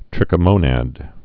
(trĭkə-mōnăd)